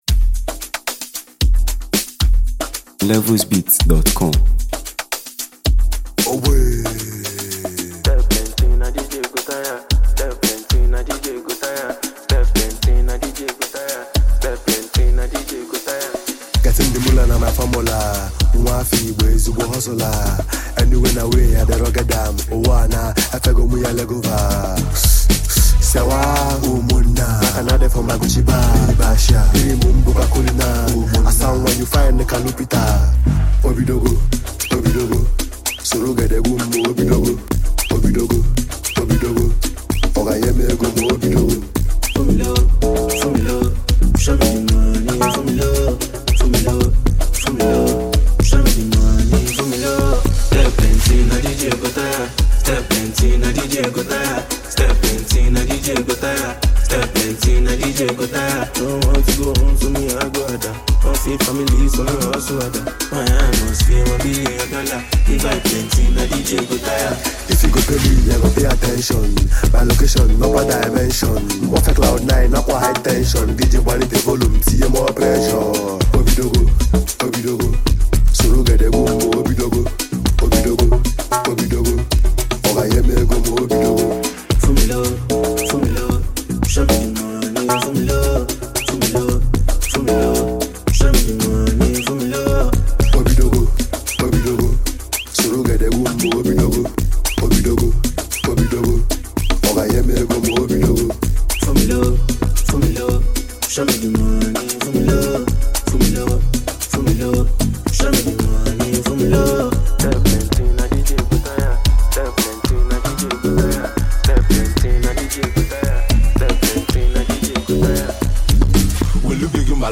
a blessed and highly favored Nigerian rap artist